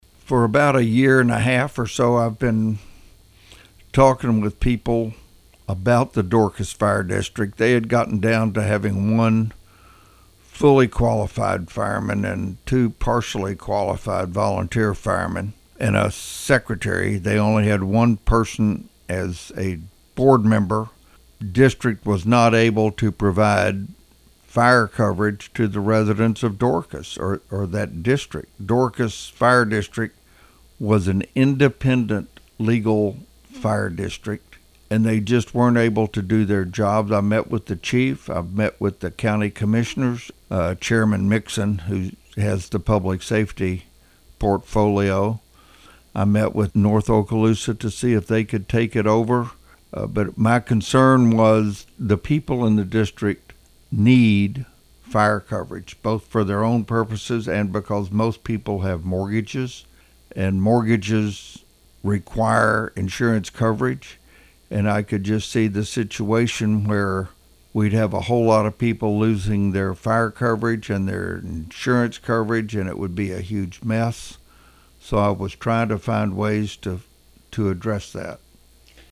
With audio from Rep. Maney
During that interview, we asked Rep. Maney what happened to cause him to get involved.